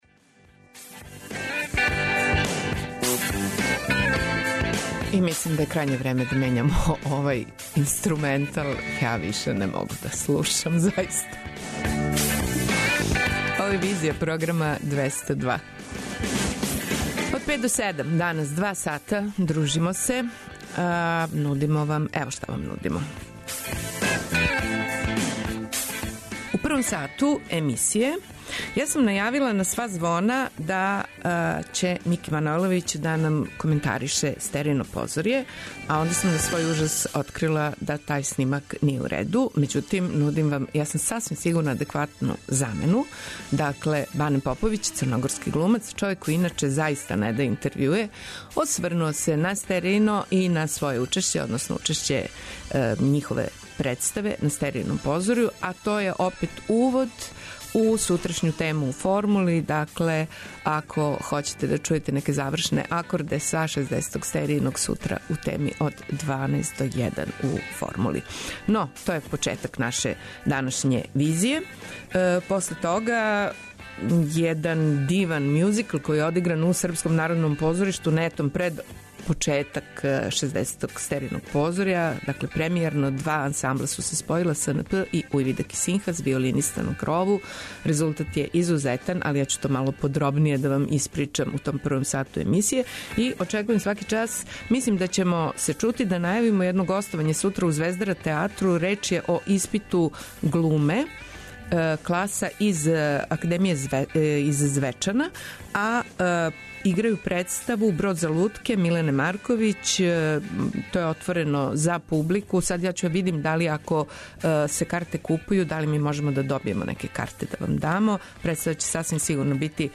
Гост емисије је Драган Великић, један од најзначајнијих савремених српских књижевника.